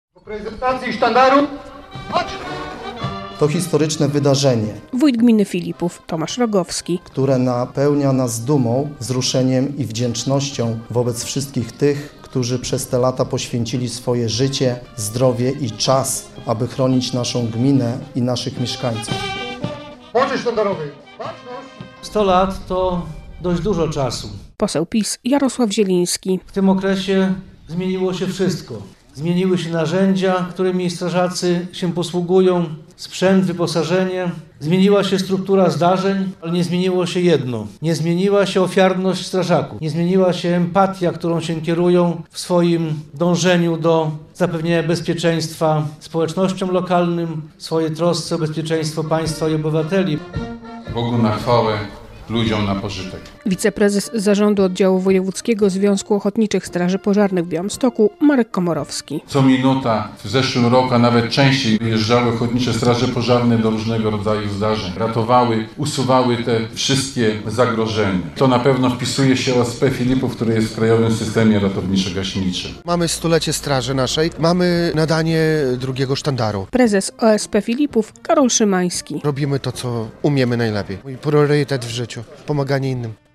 Jak podkreślał podczas uroczystości wójt gminy Tomasz Rogowski - to dzięki strażakom mieszkańcy gminy mogą czuć się bezpiecznie.